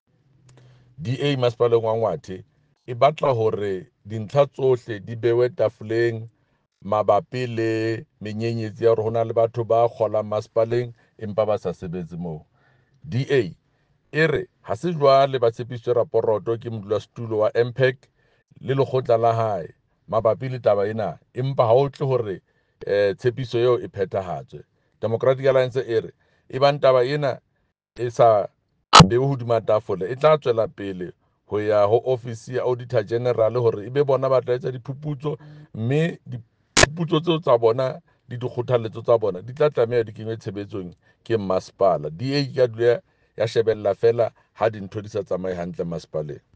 Sesotho by Jafta Mokoena MPL.